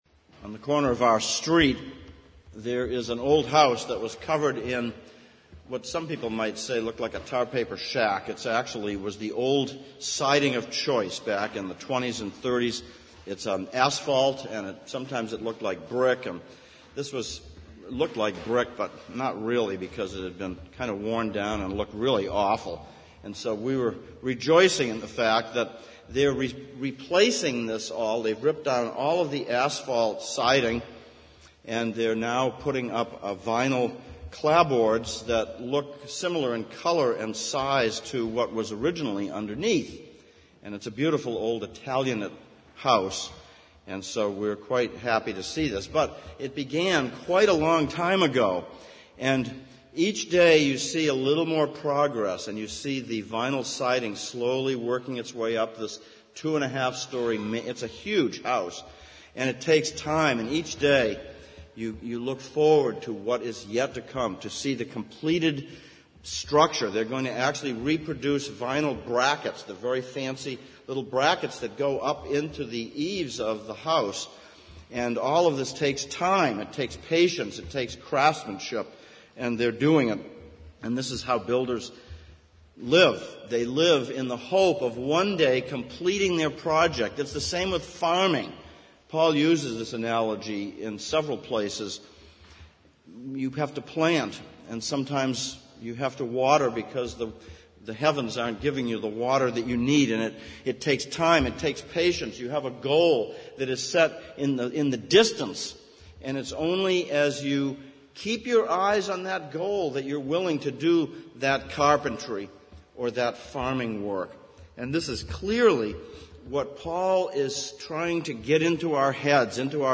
Exposition on 1 John Passage: 1 John 3:1-10, Job 19:19-29 Service Type: Sunday Morning Sermon ver. 1 John 3:1-3 « 3.